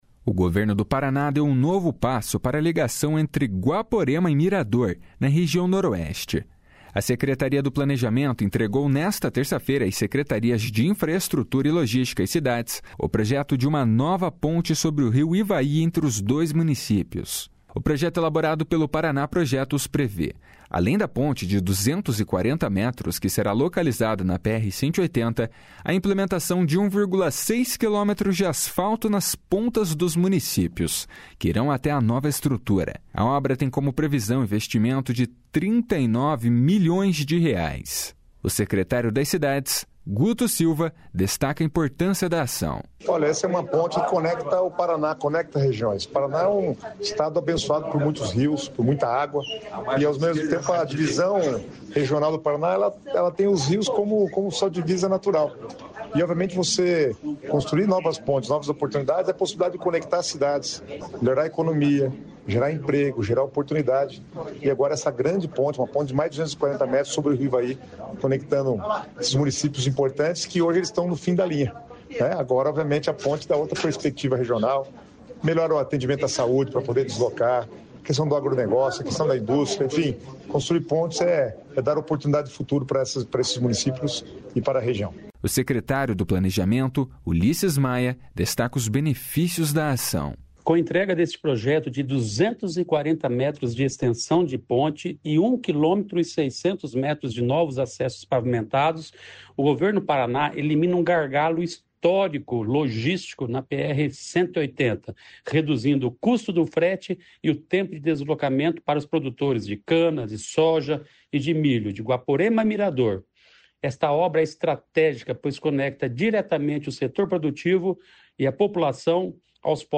O secretário das Cidades, Guto Silva, destaca a importância da ação.
O secretário do Planejamento, Ulisses Maia, destaca os benefícios da ação.
O secretário de Infraestrutura e Logística, Sandro Alex, explica sobre as ações.